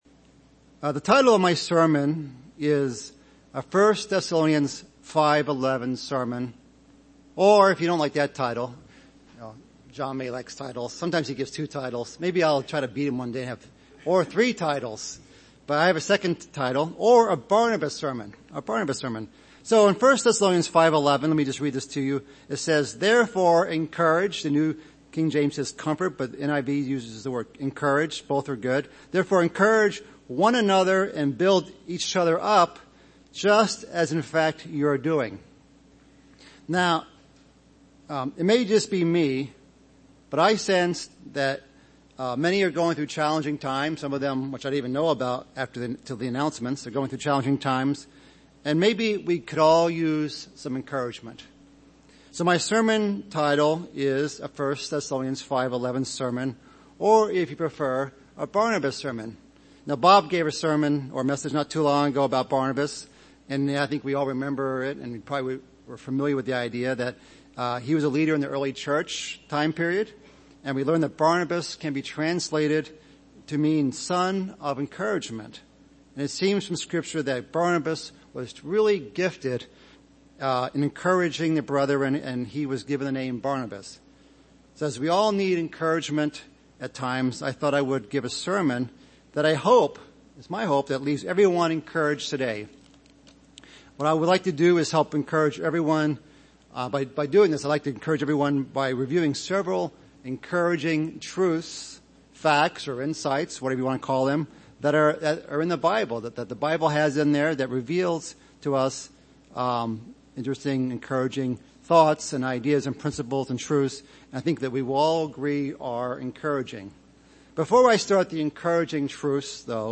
A Barnabas Sermon
Given in Chicago, IL